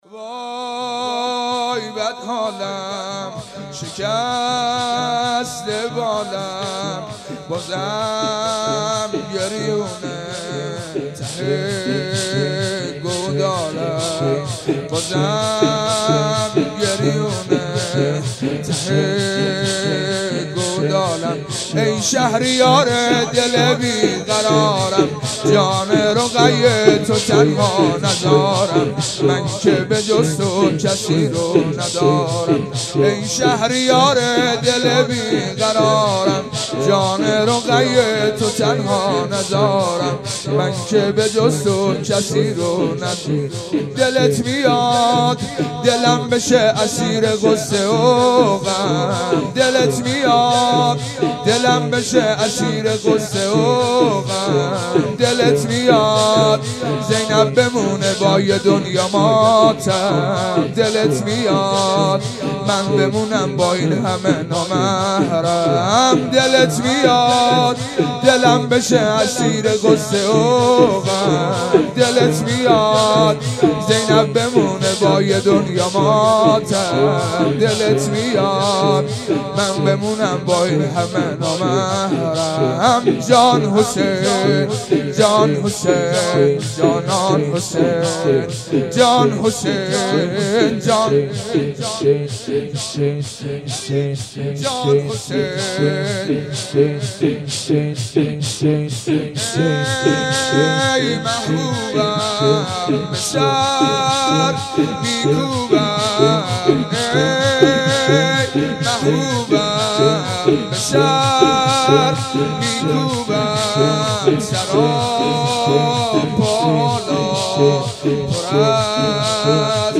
حسینیه بنی فاطمه(س)بیت الشهدا
شور